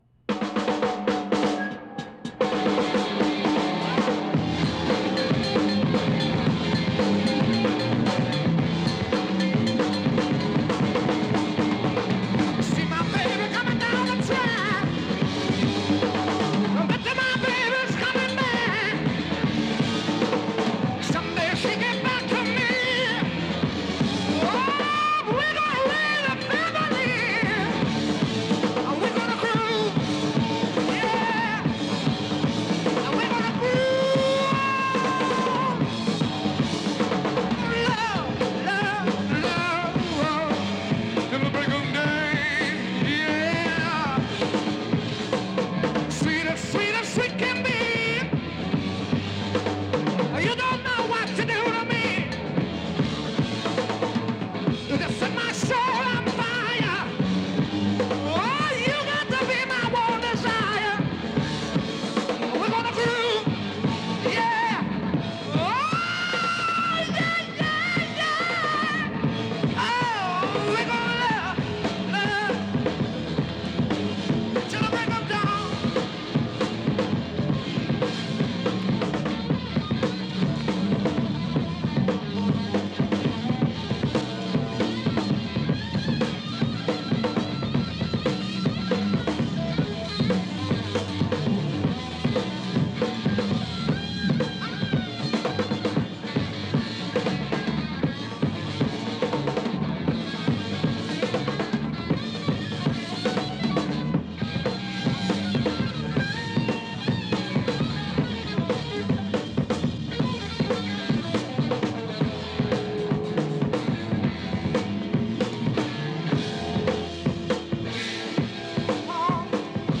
Music Genre: Hard Rock, Classic Rock, Blues Rock